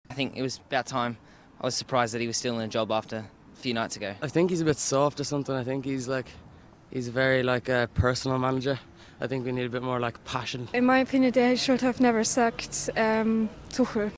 These fans outside Stamford Bridge have given their reaction to Graham Potter being sacked.